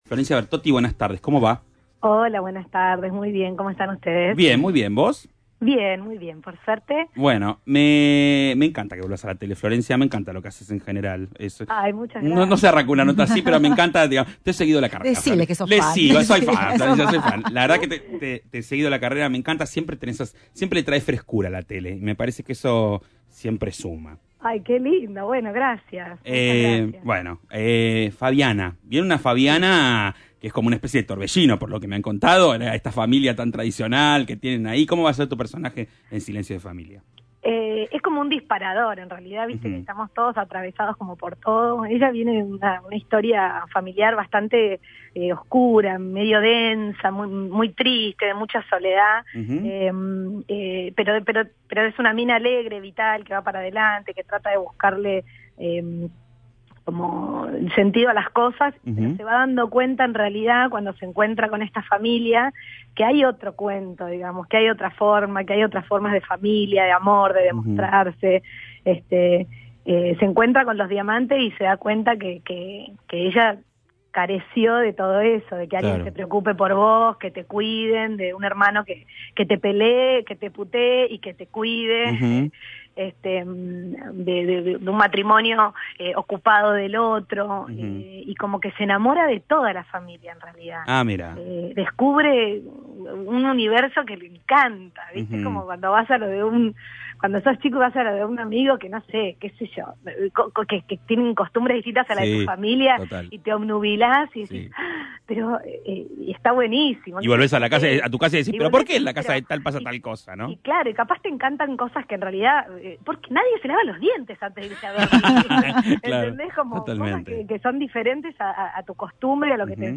Aquí les dejo una nota en la que Flor habla sobre el proyecto, que se estrena este mismo Domingo 12 de Junio a las 22H en Canal 13. Enlace nota de voz En la nota Flor habla sobre el proyecto, pero tambien habla soble otros papeles que le han marcado como "Floricienta" y otros que no le han causado nada, como Amparo en La Dueña, confirmando así que a flor no le gustó La Dueña.